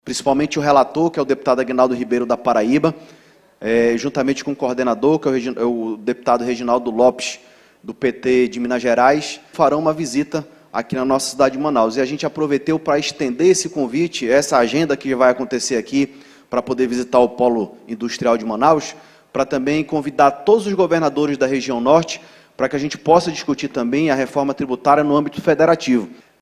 Na tribuna, Saullo afirmou que é importante manter a união política em defesa do modelo econômico amazonense.